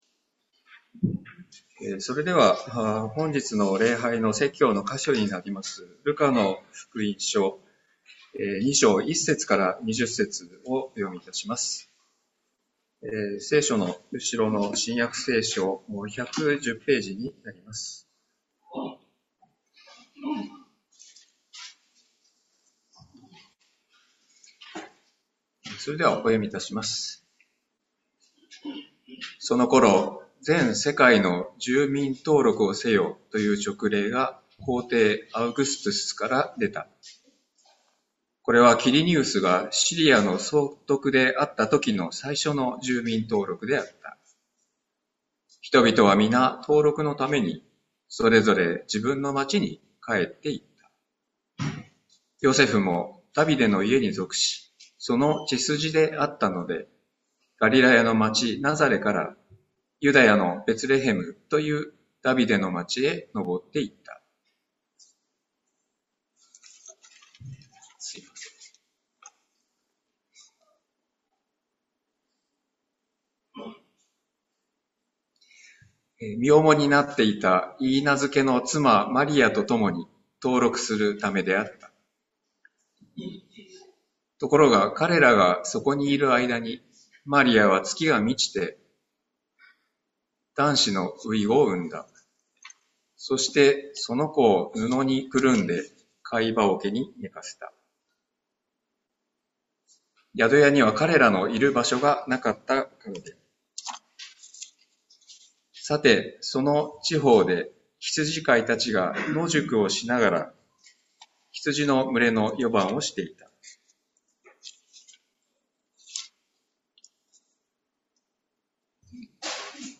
栃木県下野市の自治医大前キリスト教会では、毎週日曜日午前10時半から礼拝を行っています。